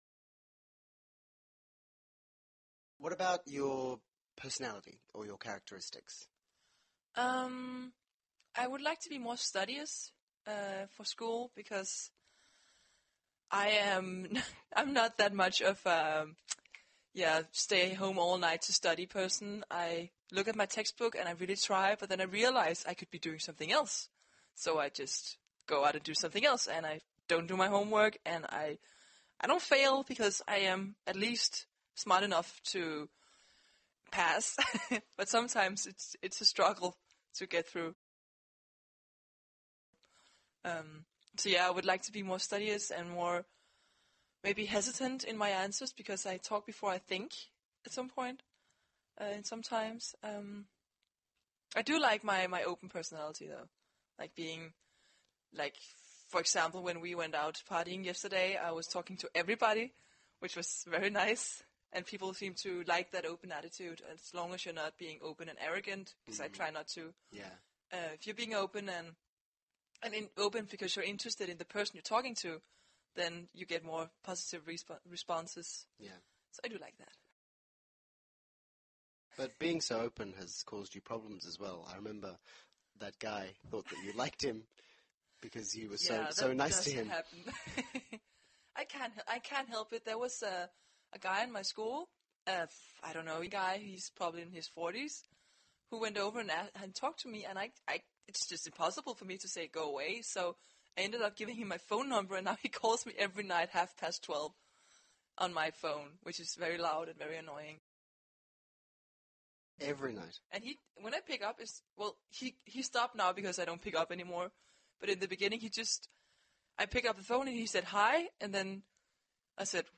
在线英语听力室英文原版对话1000个:1184 Character Changes的听力文件下载,原版英语对话1000个,英语对话,美音英语对话-在线英语听力室